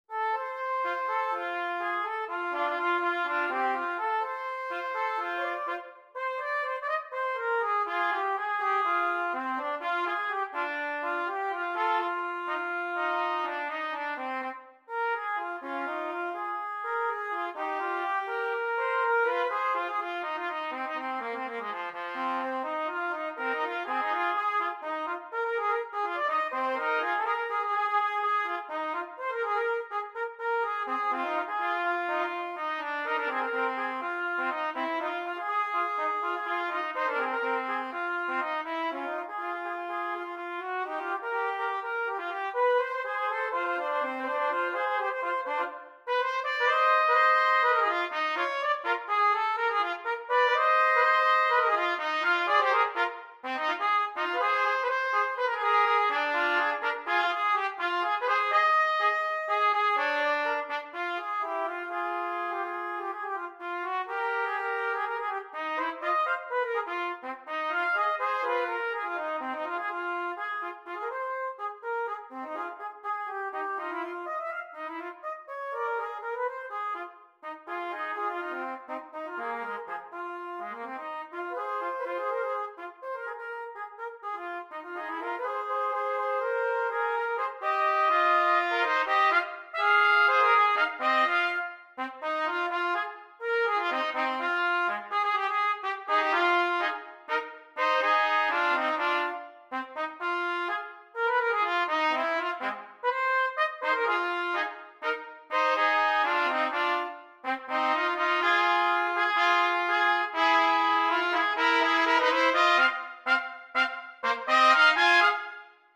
Gattung: Für 2 Trompeten
Besetzung: Instrumentalnoten für Trompete
Jazz-Duetten